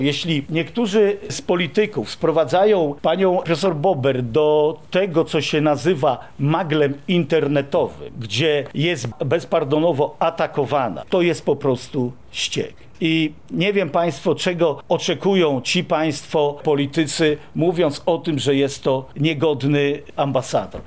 W obronie Pracowni i jej kierowniczki stanął dzisiaj (23.02) marszałek województwa lubelskiego Jarosław Stawiarski. Podczas konferencji prasowej stanowczo odrzucił zarzuty, określając je jako „polityczną manipulację i kłamstwo”.